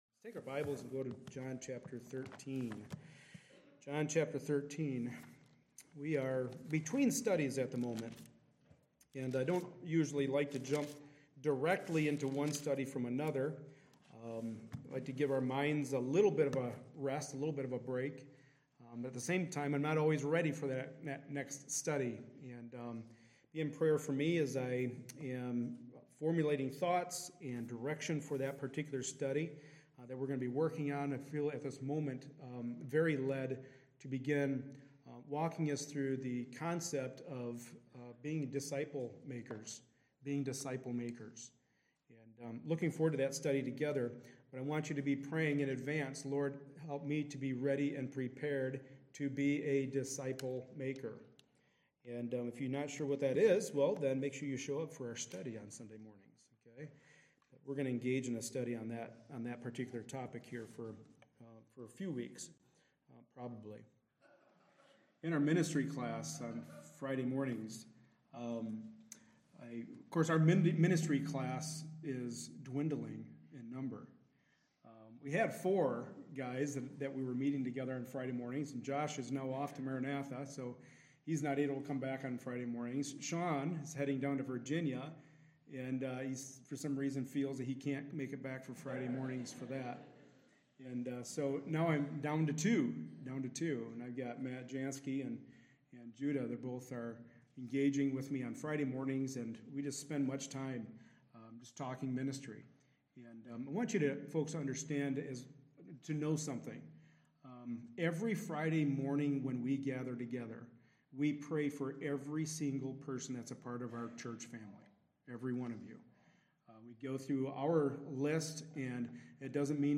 Passage: John 13:1-17 Service Type: Sunday Morning Service Related Topics